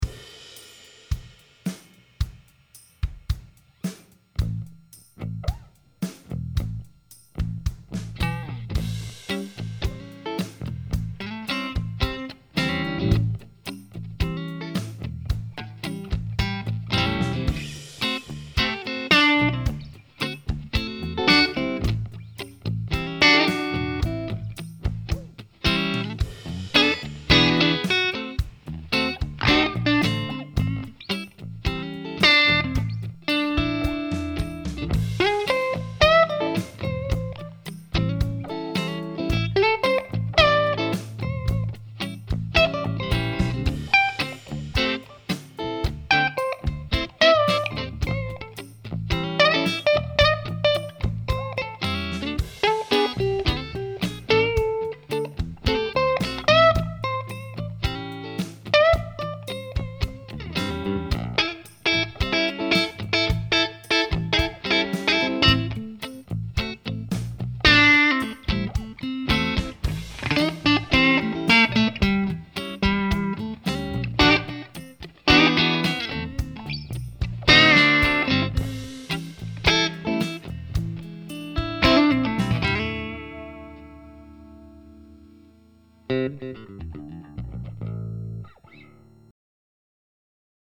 Ich habe heute Abend mal wieder etwas aufgenommen. Es ist einfach ein kleiner Blues-Jam.
Dieses lockere Spiel hat mich durch die Woche getragen.
Fender Player Strat - ToneX (Deluxe Reverb sowie Bassman Capture) Der Titel ist meiner Frau und Kindern gewidmet, die alle krank sind.